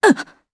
Isolet-Vox_Damage_jp_02.wav